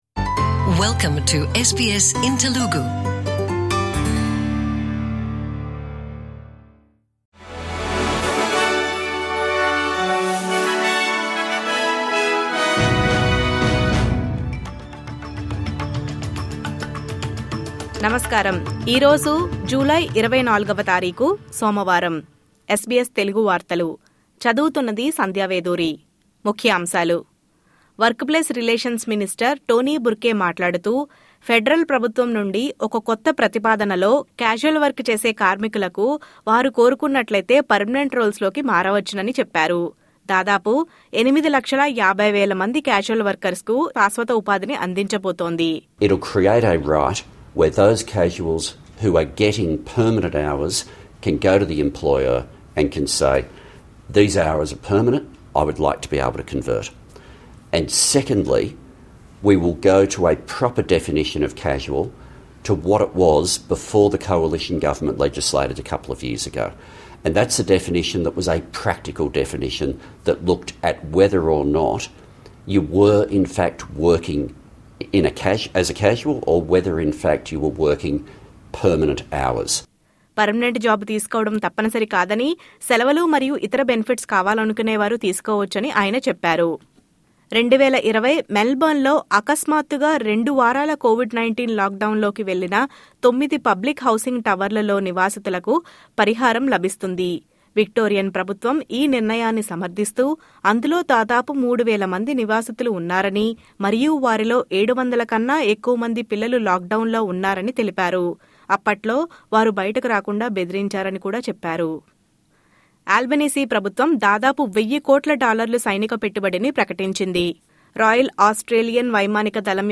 SBS Telugu వార్తలు, ముఖ్యంశాలు. 1. Casual work చేసే కార్మికులు, శాశ్వత ఉపాధి లోకి మారే అవకాశం ప్రభుత్వం కల్పిస్తోంది. 2.